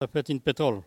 Localisation Sallertaine
Langue Maraîchin
Catégorie Locution